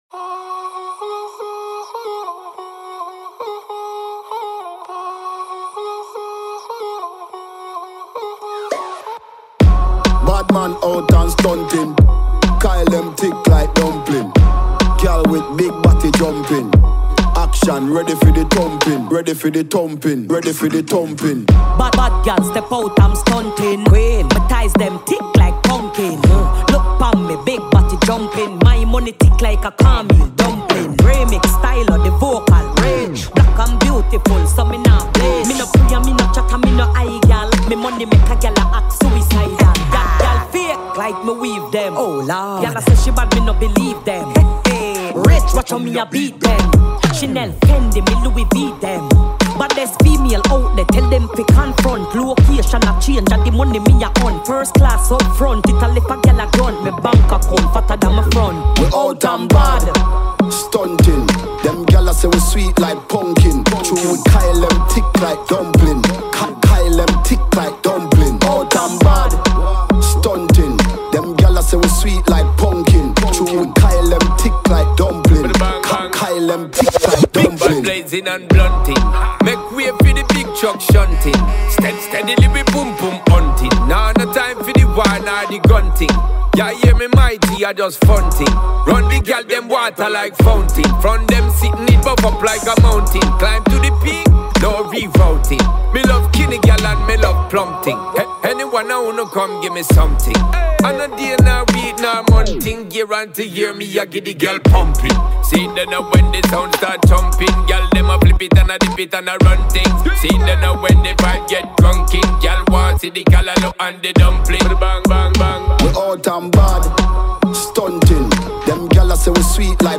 ARTISTA A LA VISTA INTERVISTA | STYLO G | Radio Città Aperta
Artista-a-la-Vista-Intervista-Stylo-G-Rototom-2024.mp3